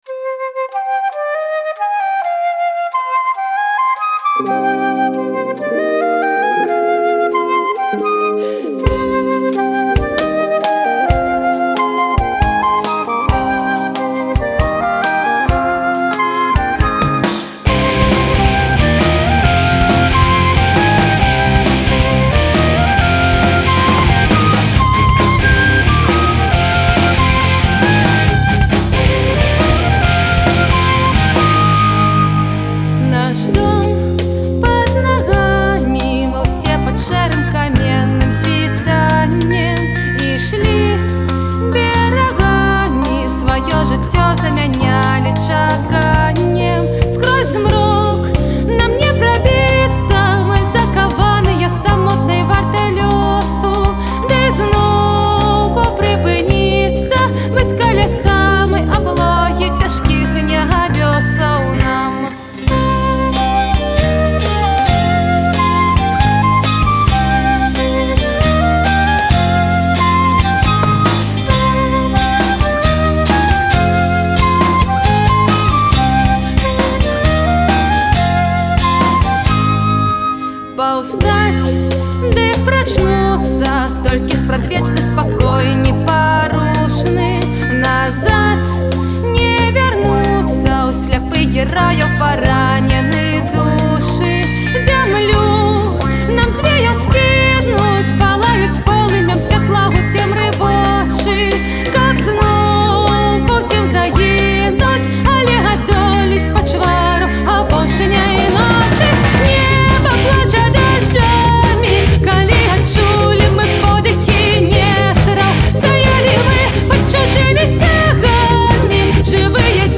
Стыль - нэафольк, акустычны фольк.